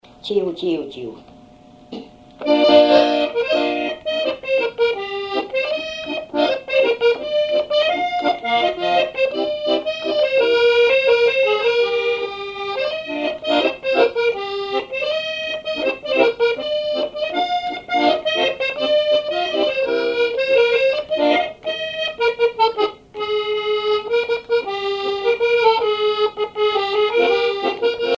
accordéon(s), accordéoniste
Répertoire à l'accordéon chromatique
Pièce musicale inédite